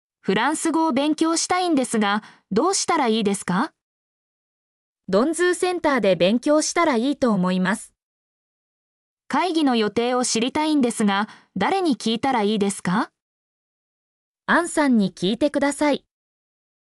mp3-output-ttsfreedotcom-2_qI7niTNM.mp3